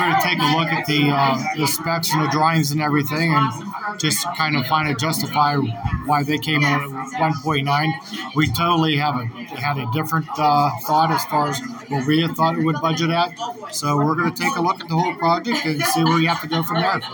Commissioners Chairman Mike Keith said that they will review the project’s specifications.